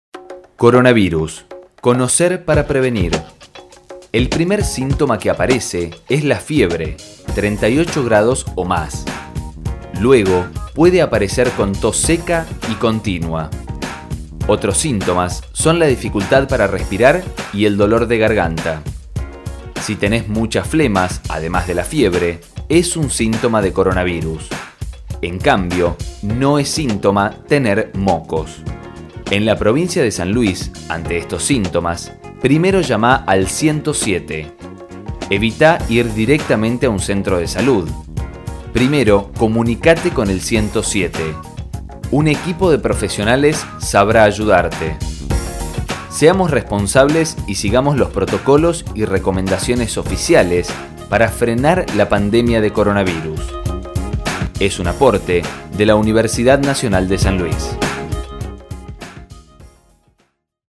A estos contenidos se suman micros radiales que serán transmitidos diariamente por Radio Universidad.